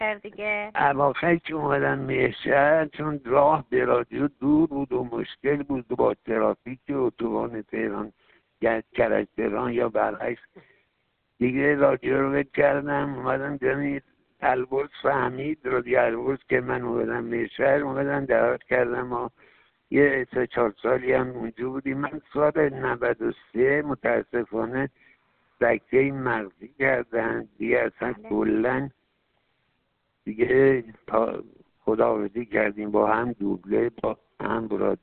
صوت | کلام آخر با صدایی حزین
زنده‌یاد جلال مقامی چندی پیش از درگذشتش آخرین مصاحبه خود را با روزنامه جام‌جم داشت و در این گفت‌وگو با صدایی نه چندان سرحال درباره روزهای بیماری، دوری‌ش از عرصه دوبله و همکاری با رادیو البرز بیان کرد: من دچار سکته مغزی شدم.